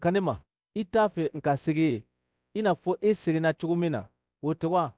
Synthetic_audio_bambara